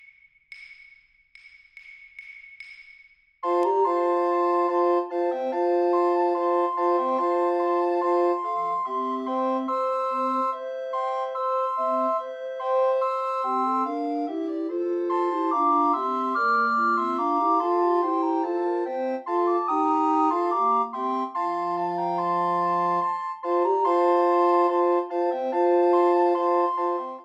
His first book of five-part madrigals, published in 1594, was a direct response to the Italian repertoire he admired, and it set the stage for his prolific output of canzonets, balletts, and madrigals. “ Fyer, fyer!
Written for five voices, it exemplifies the lively word-painting and rhythmic vitality that made his music so appealing to Elizabethan audiences. The text, full of fiery exclamations, is matched by quick, imitative entries and dance-like energy, creating a vivid musical picture of passion and excitement.